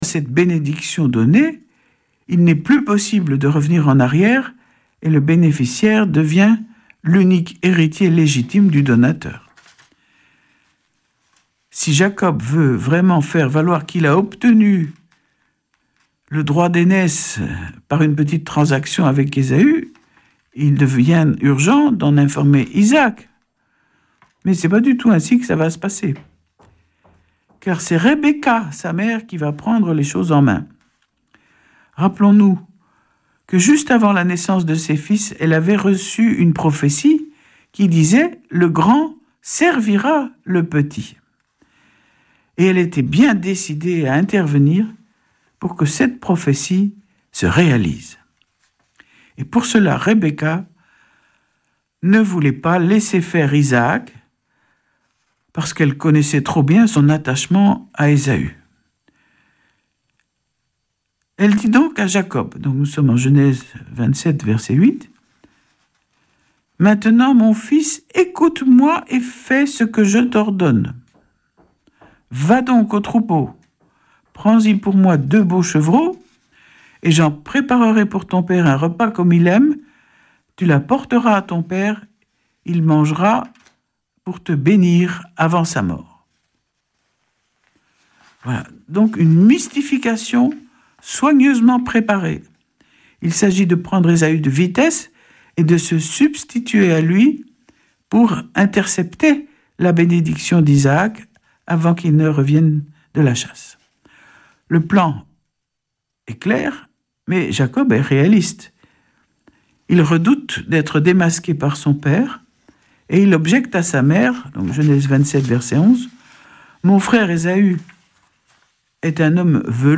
Vêpres de Saint Sernin du 13 juil.
Une émission présentée par Schola Saint Sernin Chanteurs